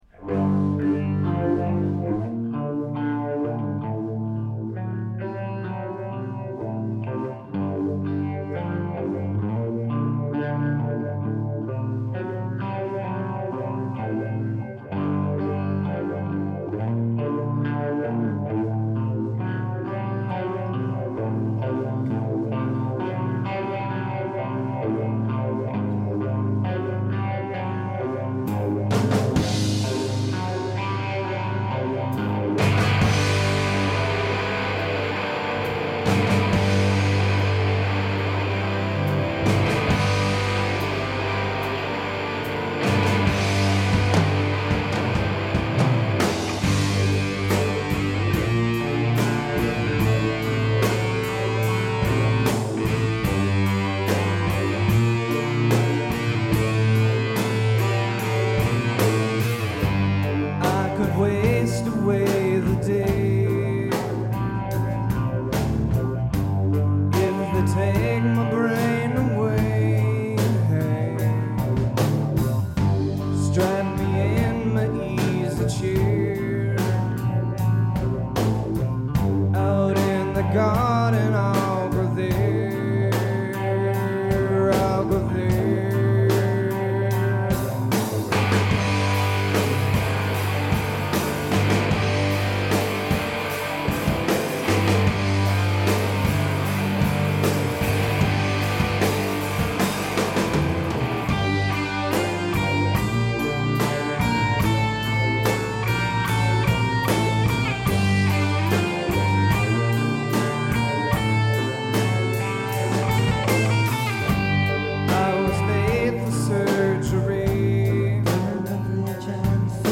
in session
early Grunge